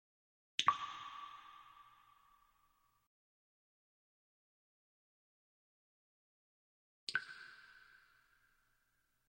Similar to track 28 (water drops)